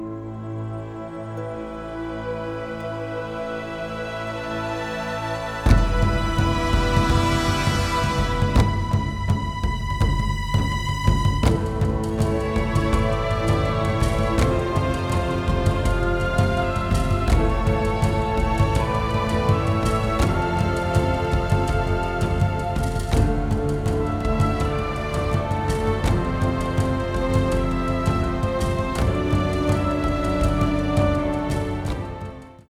pounding taiko drums